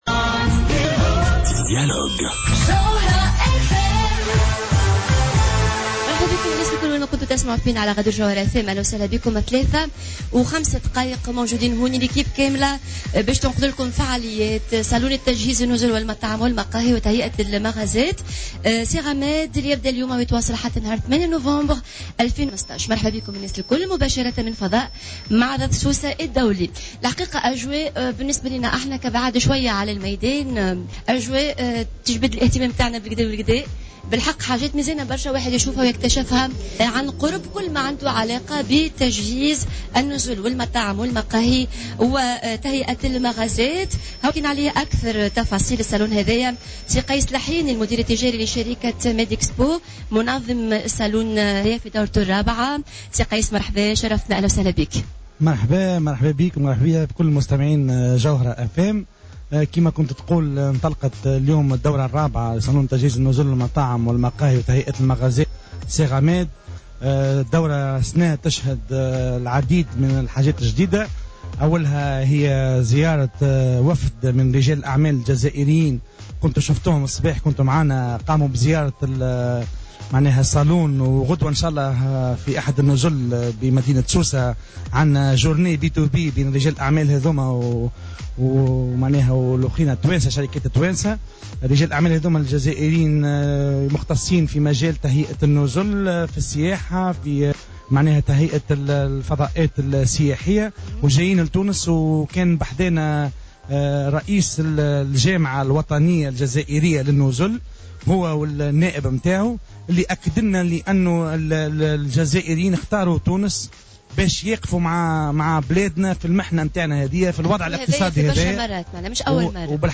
Plateau spécial depuis la Foire internationale de Sousse : Le salon Sehra Med,